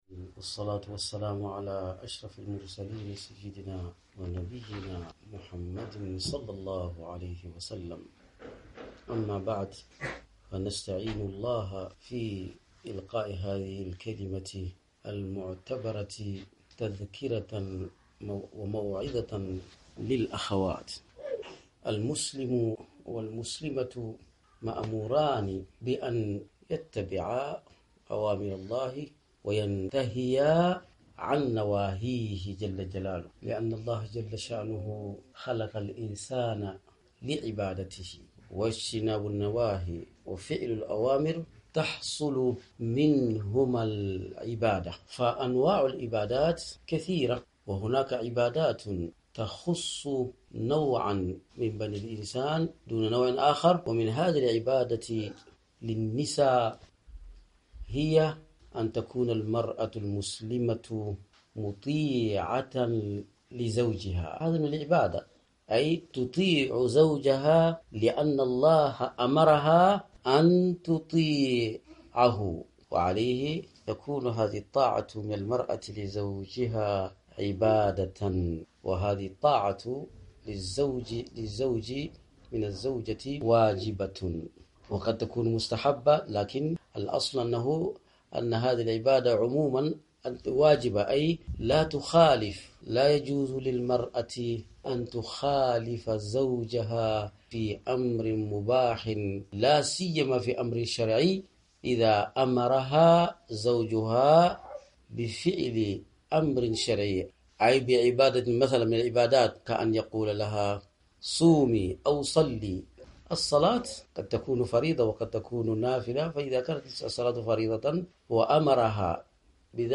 كلمة ألقيت بمكناس